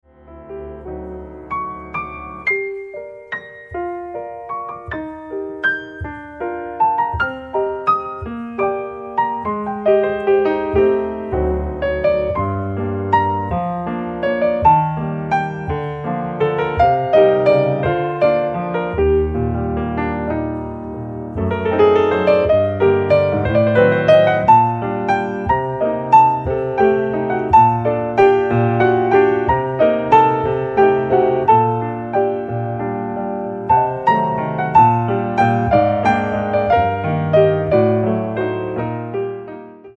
PIANO TRIO
甘く儚く、切なげに舞い上がるミラクルなメロディの応酬。